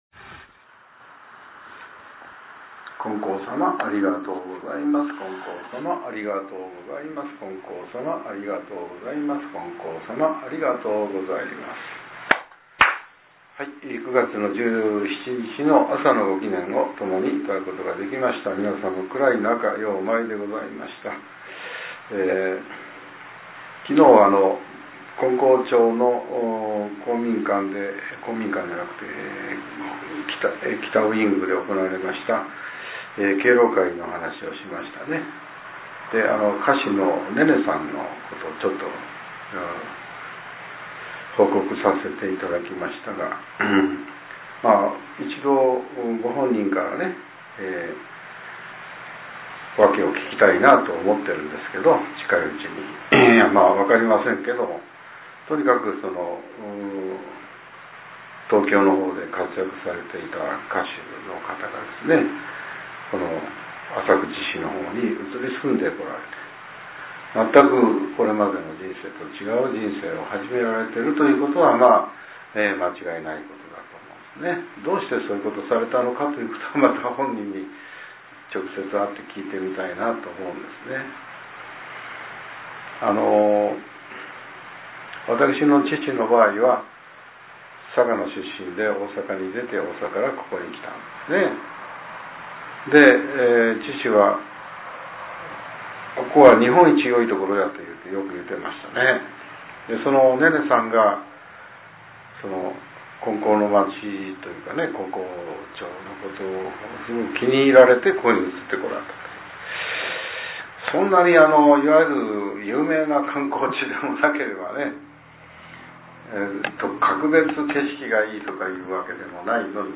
令和８年３月９日（朝）のお話が、音声ブログとして更新させれています。 きょうは、前教会長による「人と助けることができる」です。